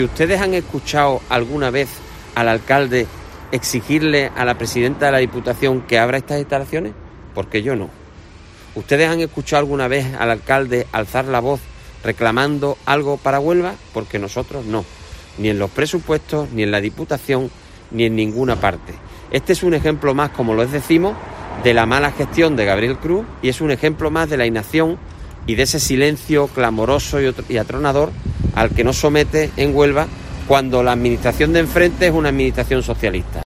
Jaime Pérez, portavoz del PP en la capital